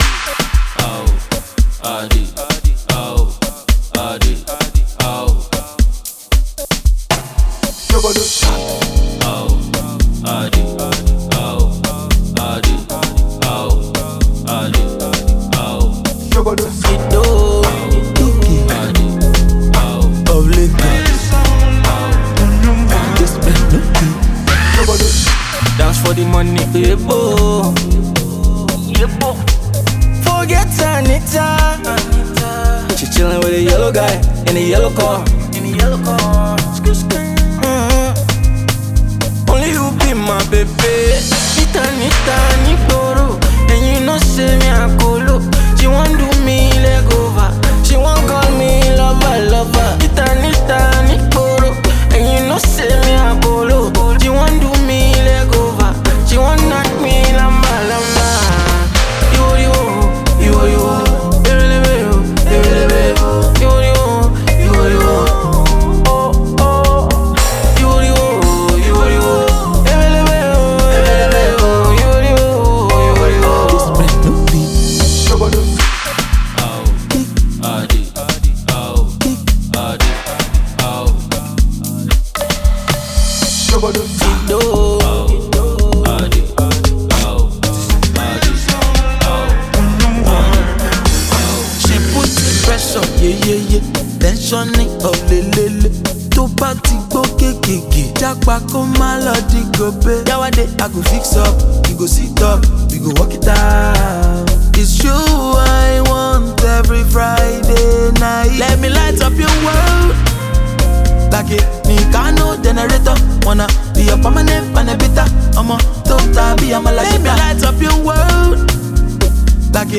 an amapiano song